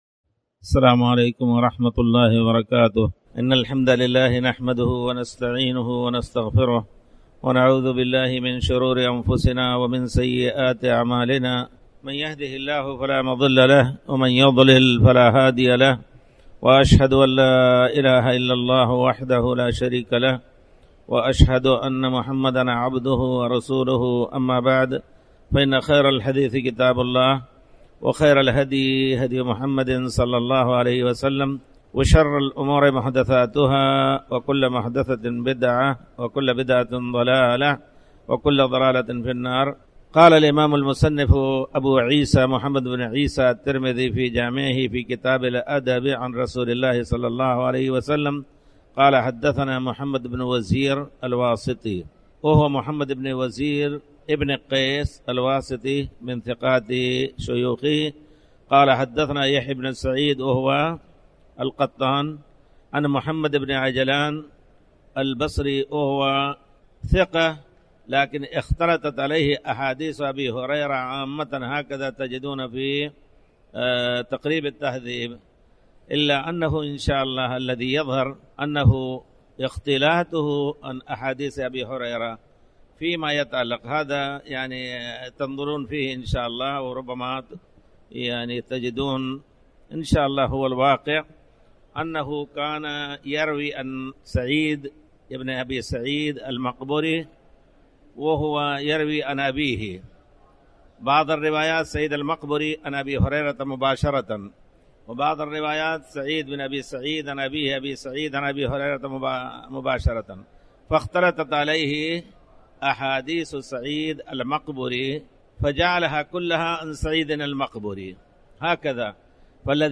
تاريخ النشر ٢٤ شعبان ١٤٣٩ هـ المكان: المسجد الحرام الشيخ